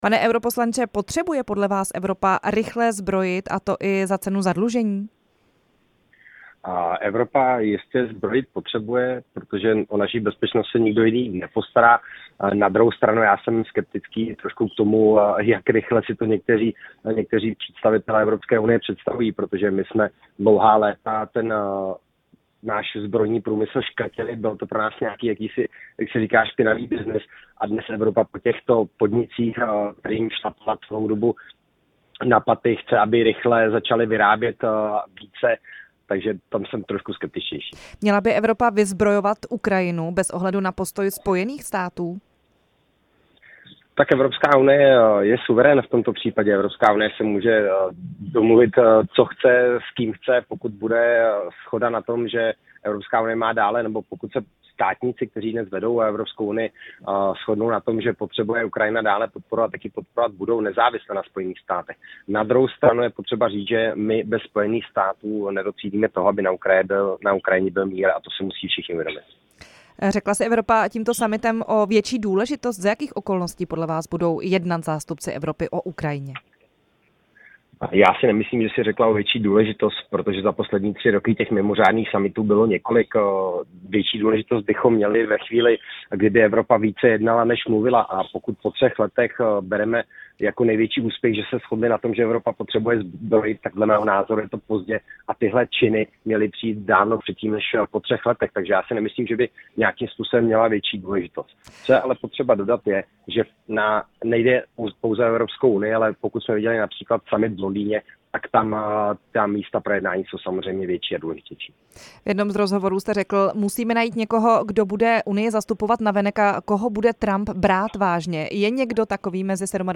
Hostem vysílání Radia Prostor byl Jaroslav Bžoch, evropský poslanec hnutí ANO.
Rozhovor s Jaroslavem Bžochem, evropským poslancem hnutí ANO